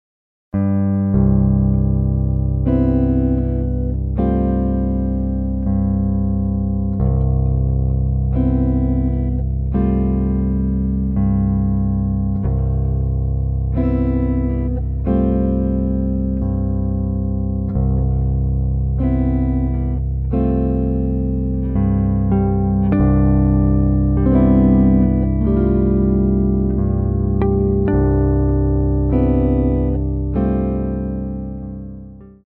solo guitar arrangements